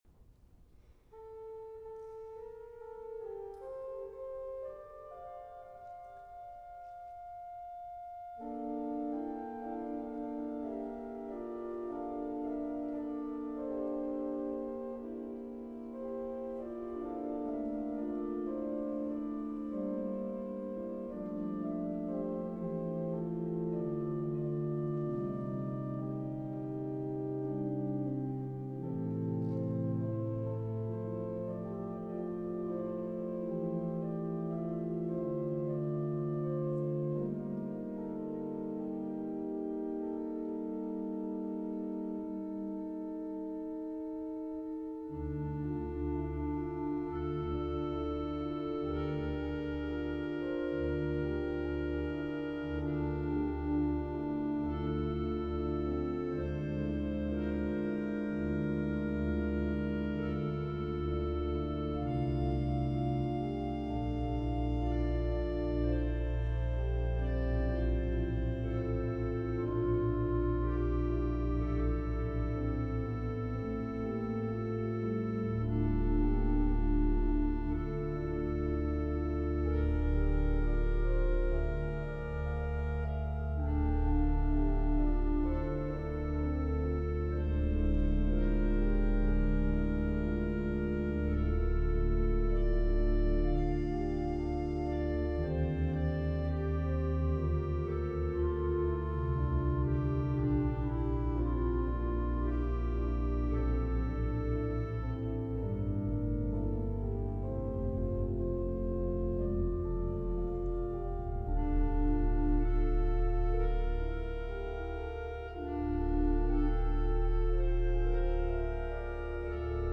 Die durchschlagende Clarinette